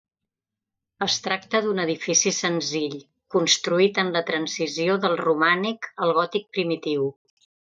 Pronounced as (IPA) /dəl/